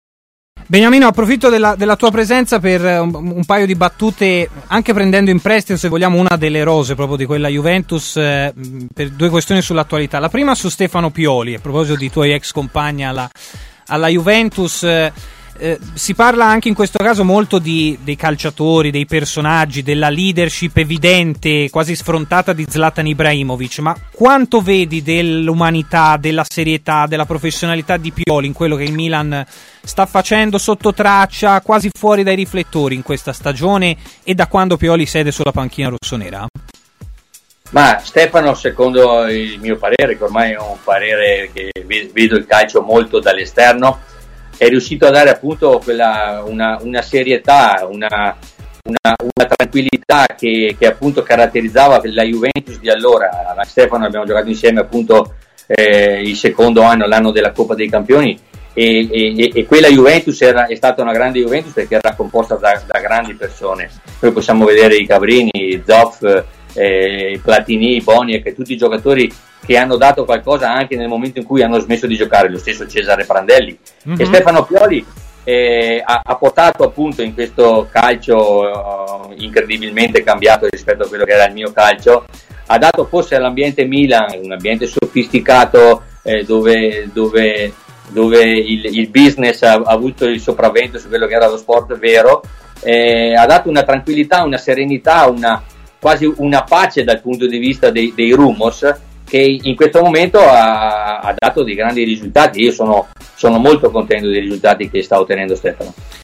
L'ex centrocampista Beniamino Vignola, intervistato da TMW Radio, ha parlato così del lavoro di Stefano Pioli al Milan: "Ha dato serietà e tranquillità, la stessa che caratterizzava la Juventus di allora.